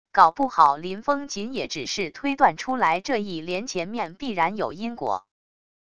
搞不好林封谨也只是推断出来这一联前面必然有因果wav音频生成系统WAV Audio Player